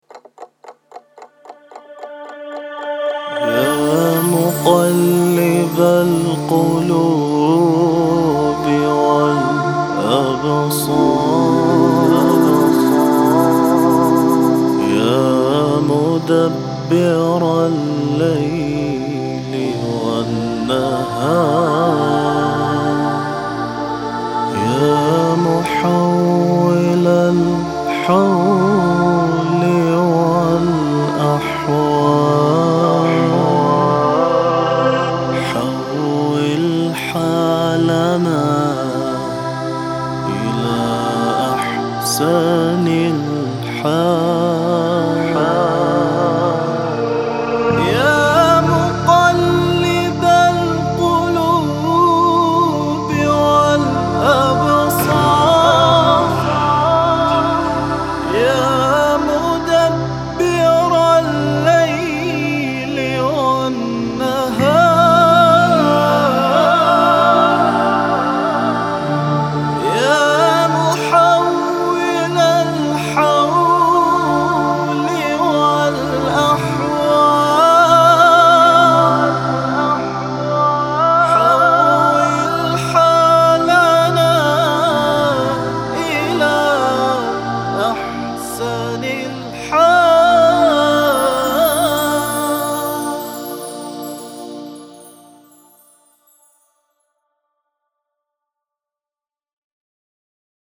دعای تحویل سال نو